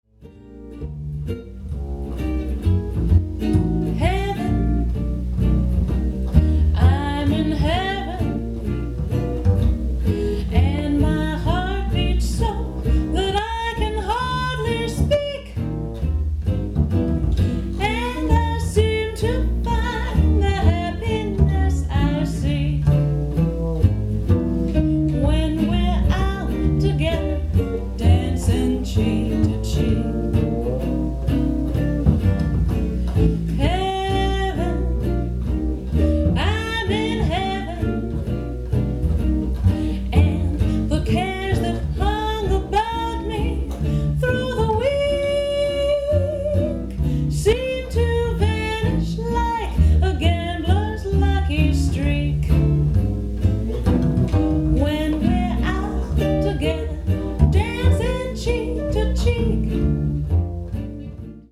bass.
guitar